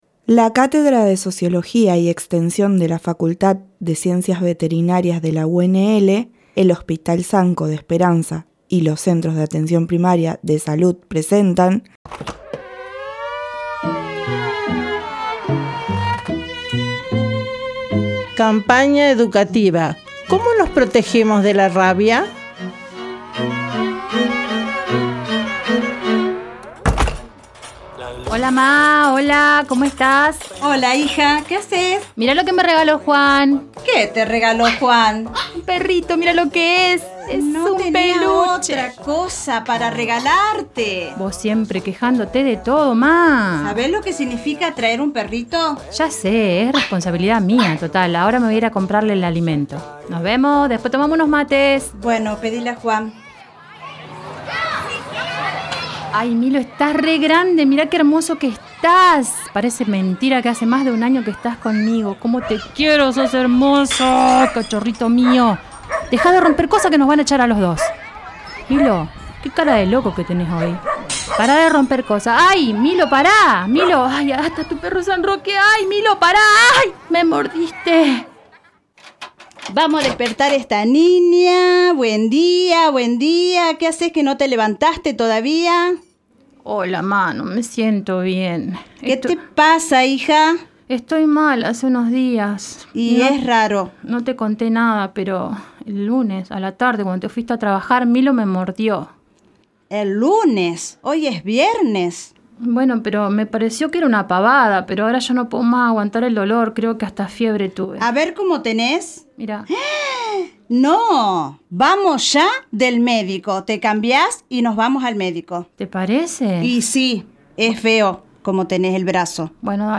Como resultado de los Talleres, se produjeron dos micros radiales abordando el problema del Dengue y la Rabia, tanto los síntomas de la enfermedad, las vías de transmisión y las recomendaciones preventivas. Cada pieza comienza con una breve dramatización cuyas protagonistas fueron las mismas vecinas del barrio, donde se buscó plasmar situaciones cotidianas que se reproducen en diferentes barrios, intentando que los receptores se sientan identificados e interpelados.